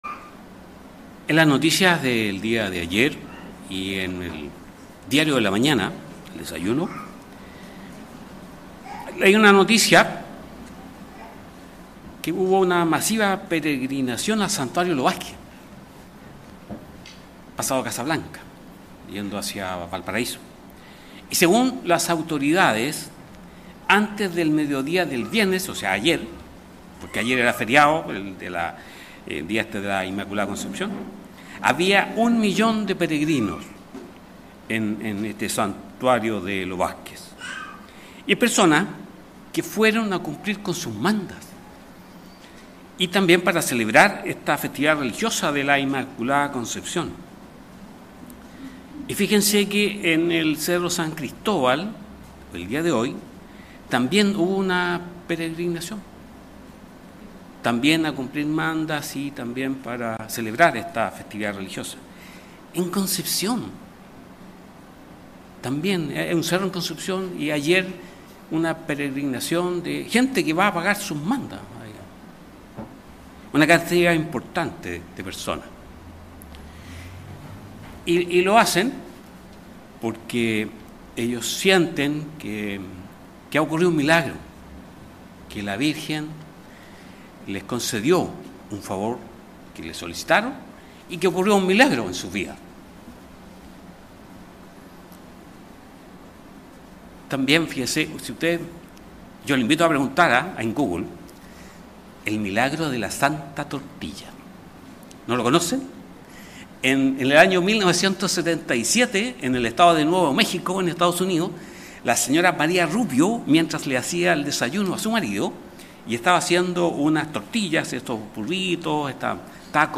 Sermones
Given in Santiago